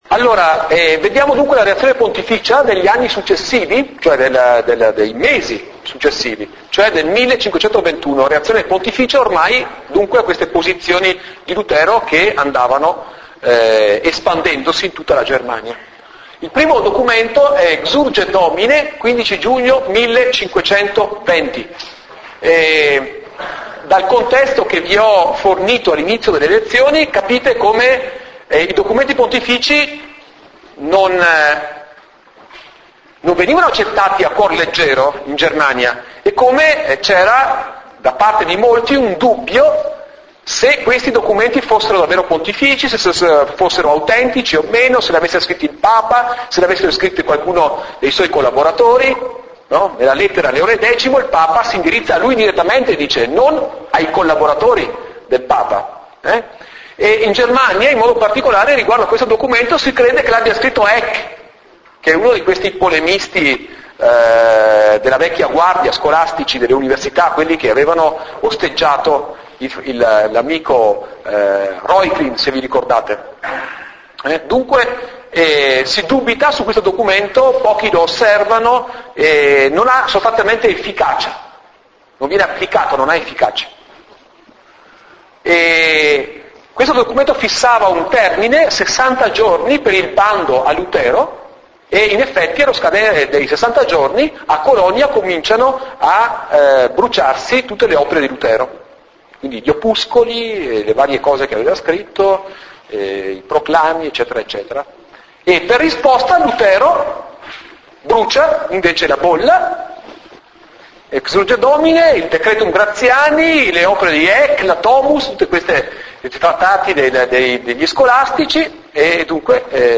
In Italian: 10� Lezione